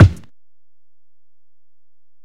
Kick (11).wav